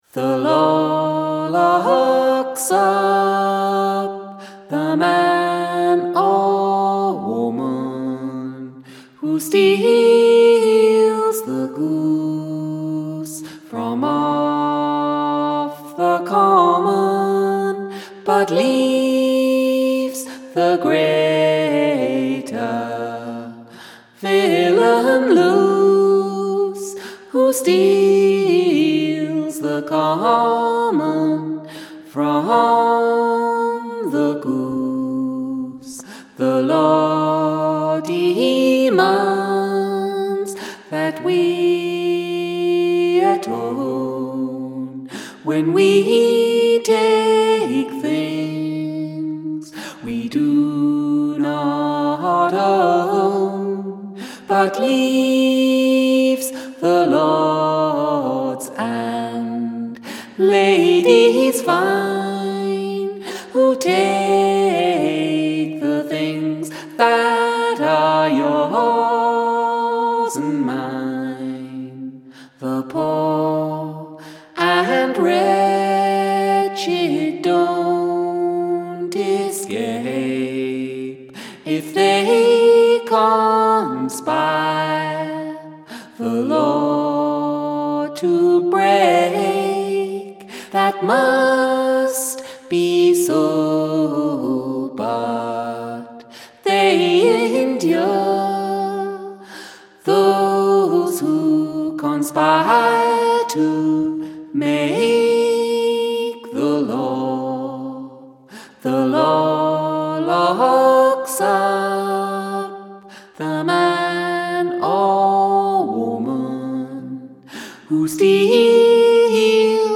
Rounds and Canons
The-Goose-and-Common-a-cappella.mp3